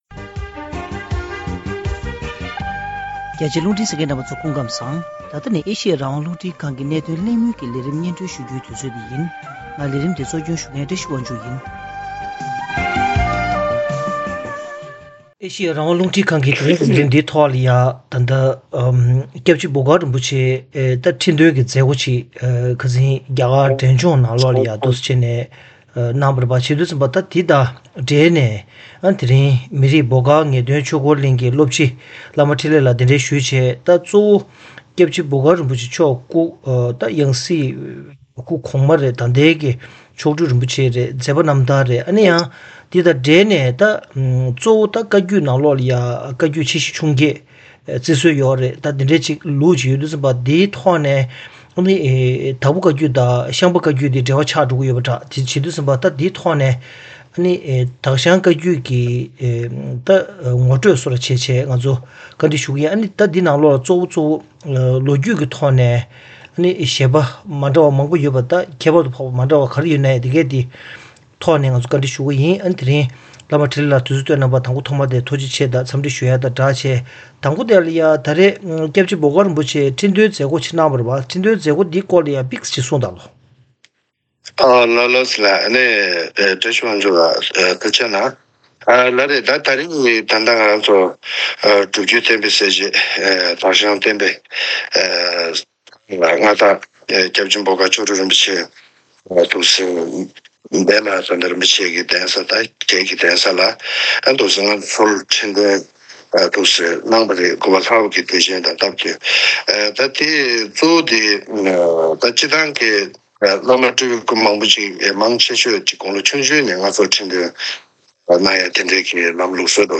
གླེང་མོལ་ཞུས་པར་གསན་རོགས་གནང་།།